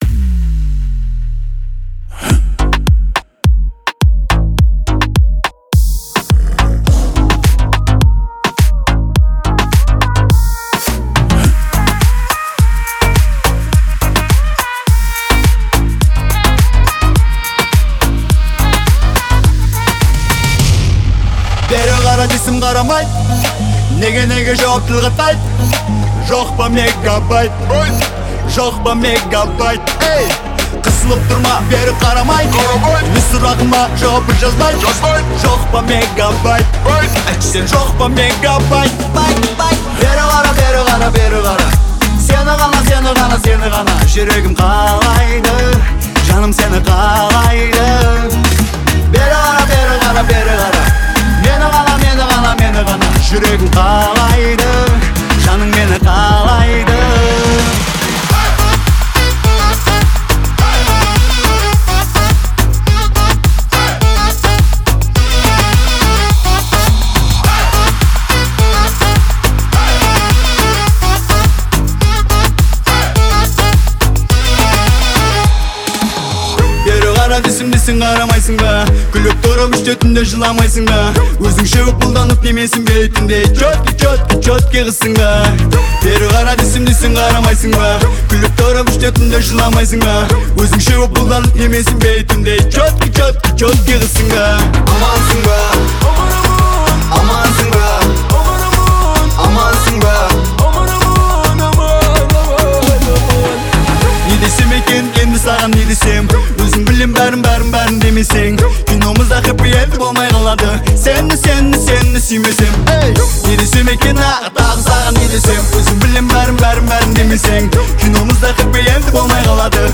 В ней царит позитивное и жизнеутверждающее настроение.